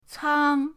cang1.mp3